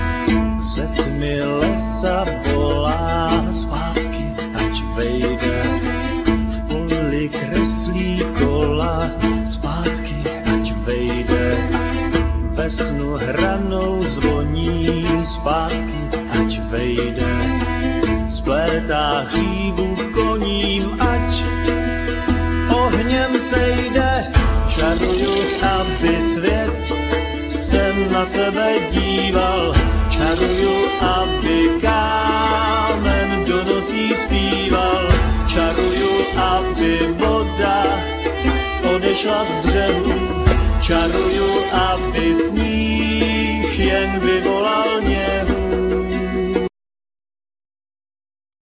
Flute,Voice
Violin,Viola,Voice
Double bass
Darbuka,Djembe
Sitar,Voice